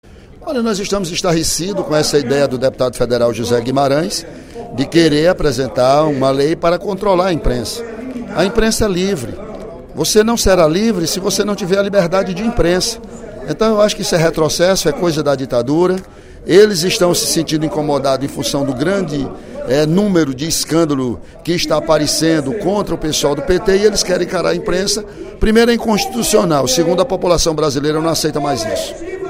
Durante o primeiro expediente da sessão plenária desta sexta-feira (11/04), o deputado Ely Aguiar (PSDC) defendeu a liberdade de imprensa no País.